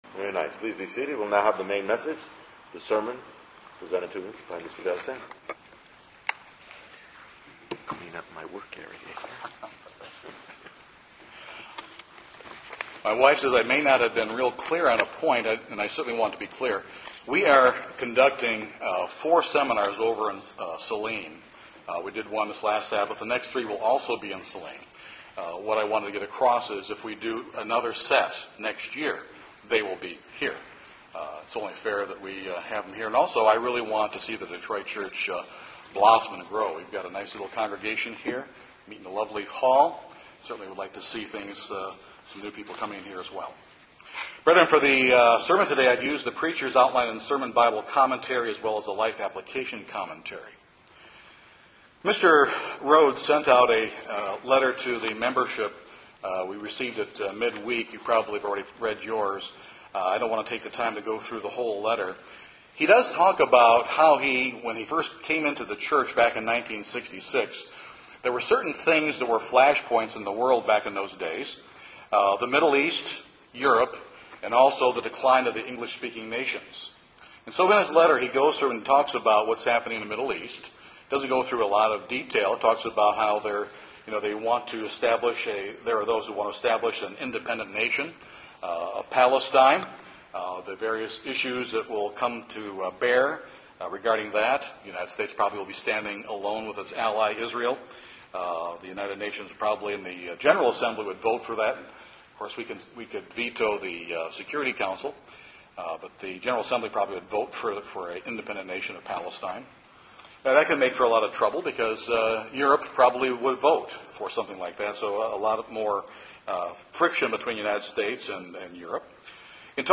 The gospel of the Kingdom of God should not be something that only inspires us at certain times, but all the time, along with transforming us. This sermon will discuss that concept in detail.